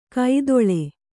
♪ kaidoḷe